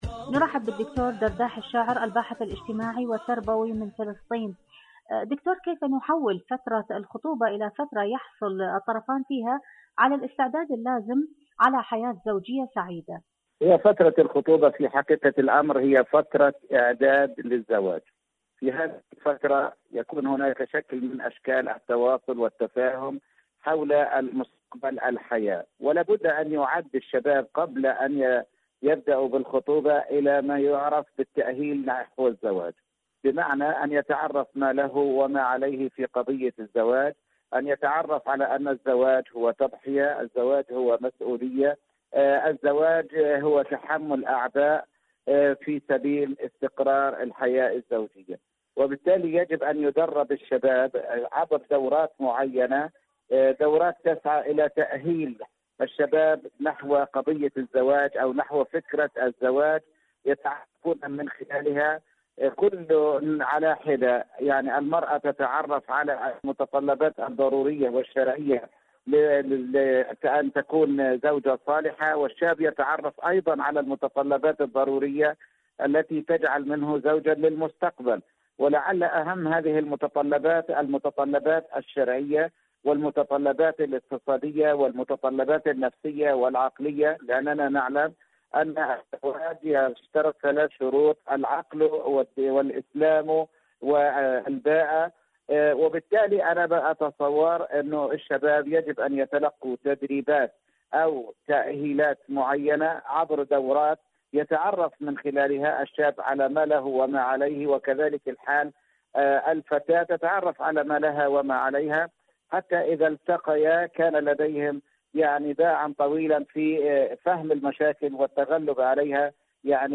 الشباب وفترة الخطوبة.. مقابلة